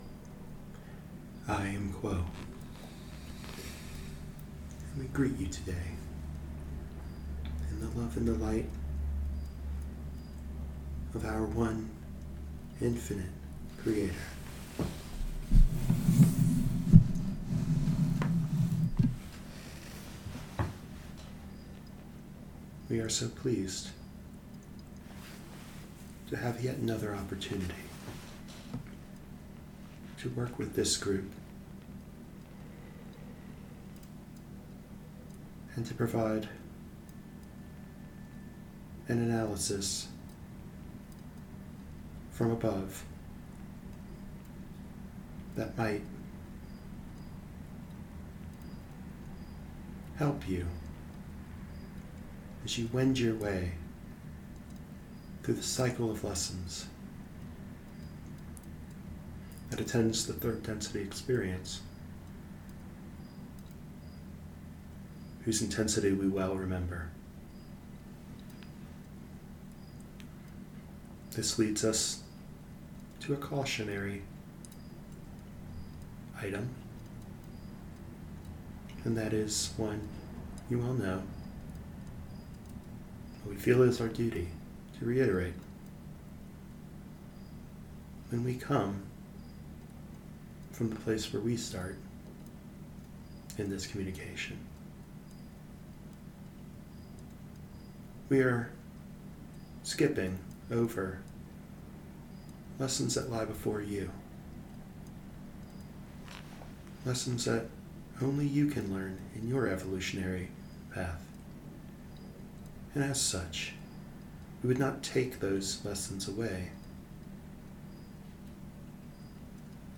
In this session from the Other Selves Working Group’s first channeling intensive in Colorado Springs, Q’uo lays the groundwork for social dynamics that span from the one-on-one relationship to the large institution, showing how these different scales of association allow for the issue of energies that allow us to work with the lessons of the yellow ray center. Conflict, humiliation, and frustration are far from errant experiences – they are the means by which we learn the path towards social memory.